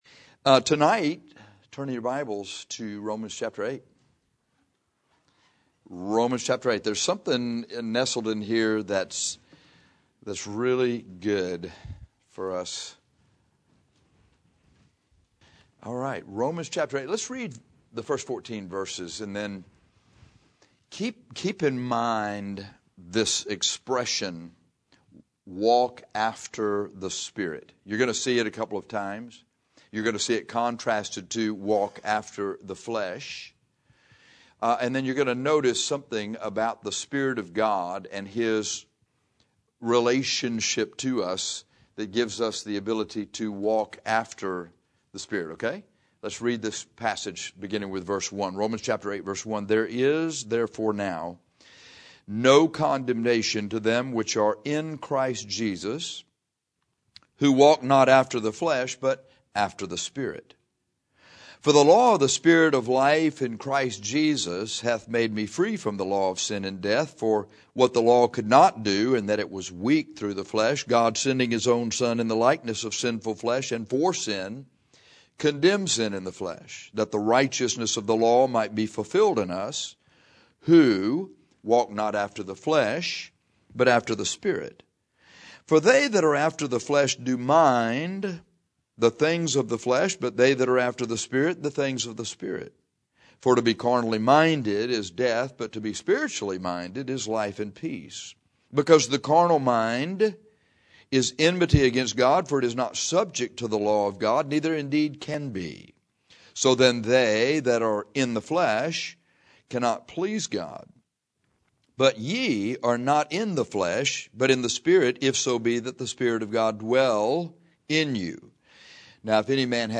In Rom 8:1-14, you are told to walk after the Spirit. This sermon shows how to follow the Spirit and why you can.